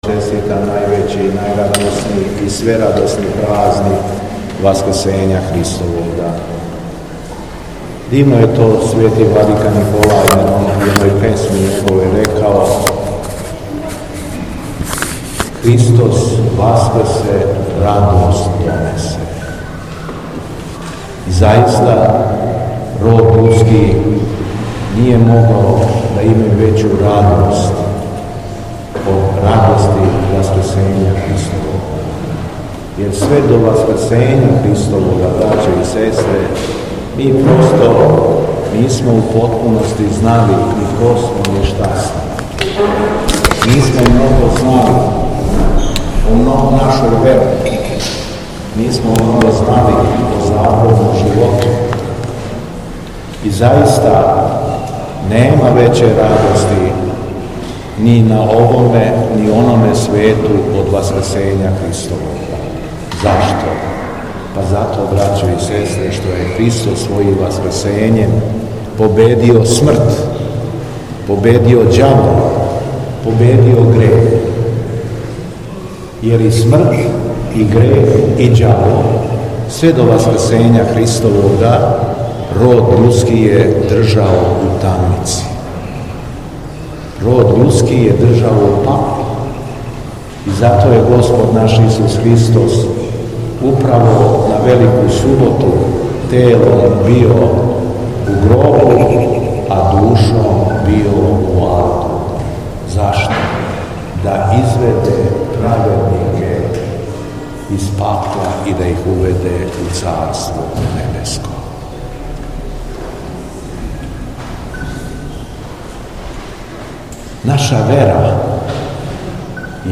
На Васкрс, 5. маја 2024. године, Његово Преосвештенство Епископ шумадијски Господин Јован служио је Пасхално вечерње у Цркви Светих апостола Петра и Павла у Јагодини.
Беседа Његовог Преосвештенства Епископа шумадијског г. Јована
Архијереј Јован је произнео надахнуто васкршње слово: